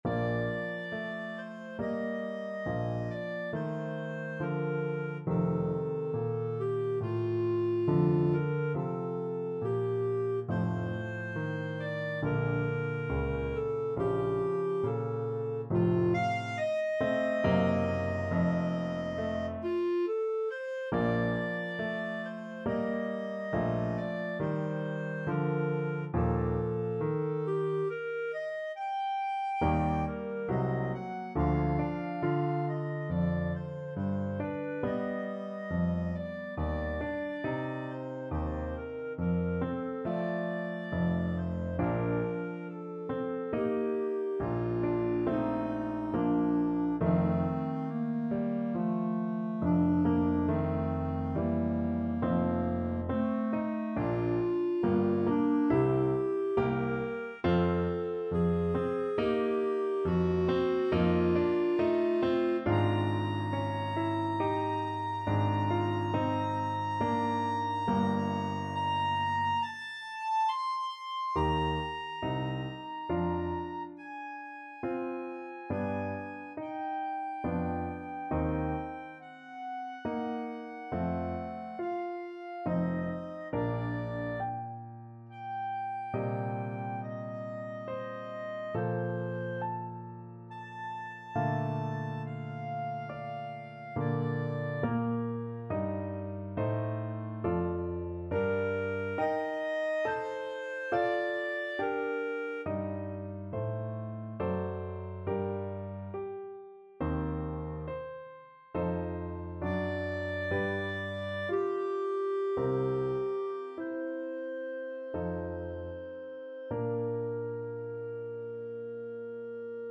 6/4 (View more 6/4 Music)
Andante =c.84 =69
Classical (View more Classical Clarinet Music)